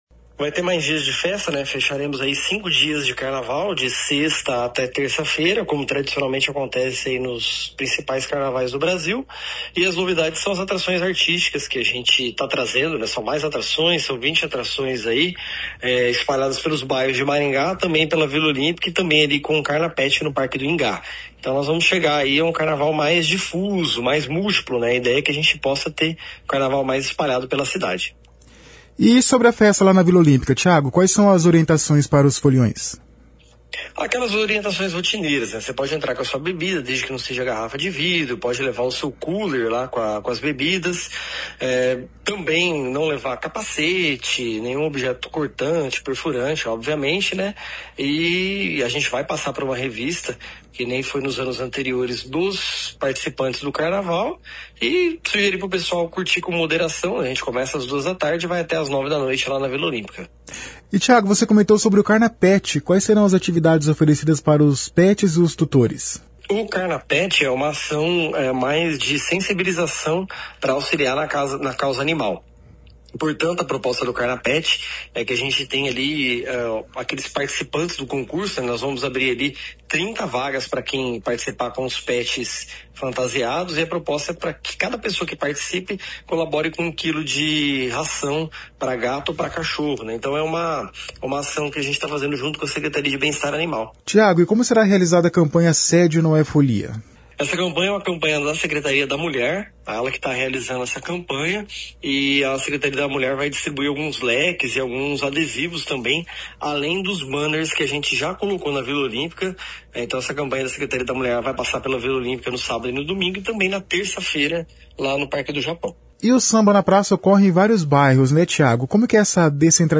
O secretário de Cultura, Tiago Valenciano, comenta as principais novidades.